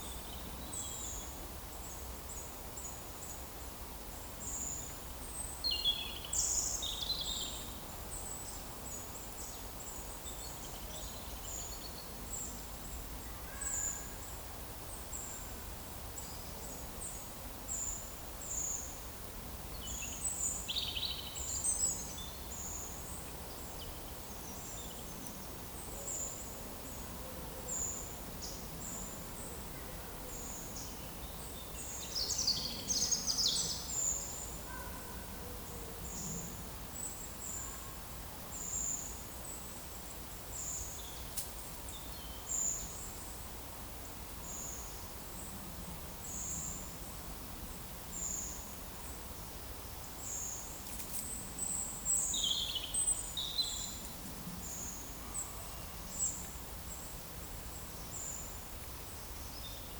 Monitor PAM
Certhia brachydactyla
Certhia familiaris
Erithacus rubecula
Turdus iliacus